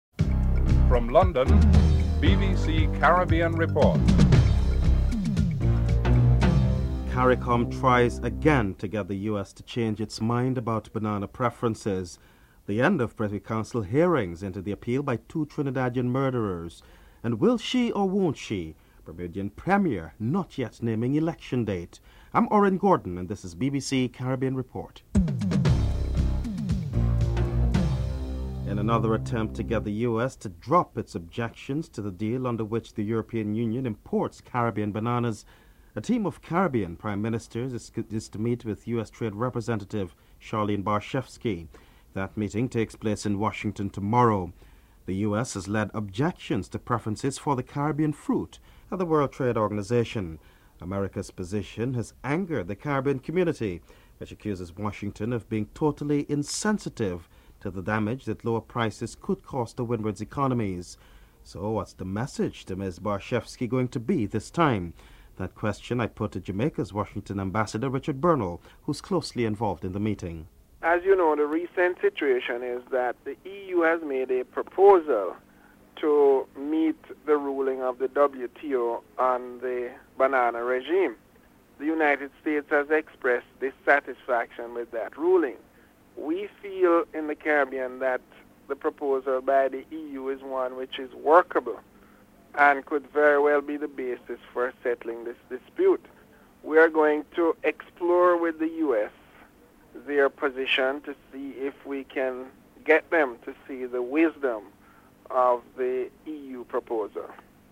Jamaica Washington Ambassador Richard Bernal and St. Lucia's Agriculture Minister Cassius Elias are interviewed.